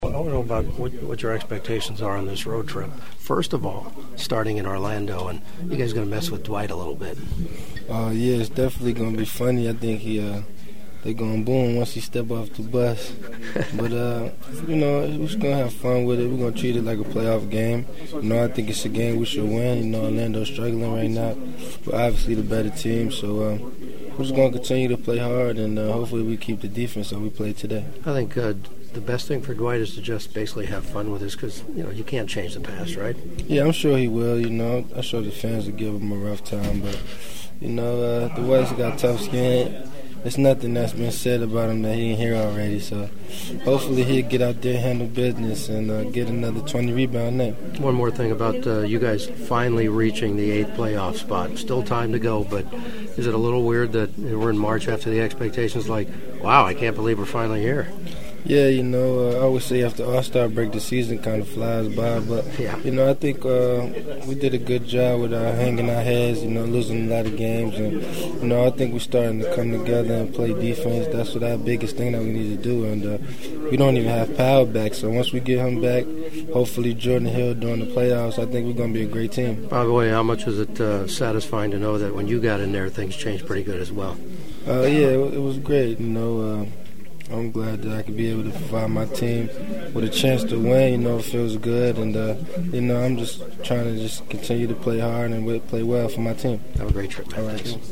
Either way the Lakers were all smiles in the postgame locker room and the following sound is for you to digest as they’re now 2 games over .500 for the first time this season before hitting the road for the next 3 games starting in Orlando on Tuesday night.